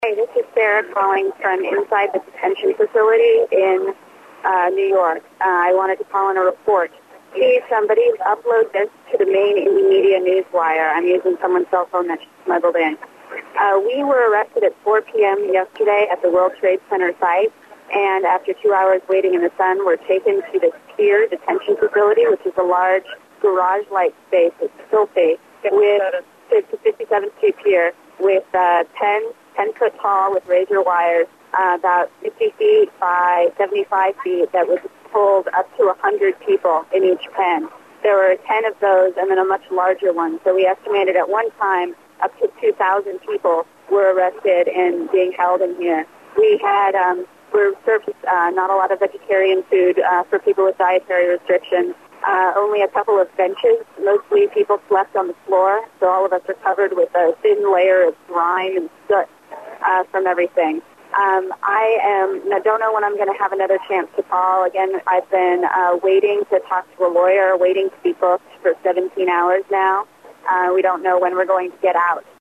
Report from Inside Pier 57 Detention Facility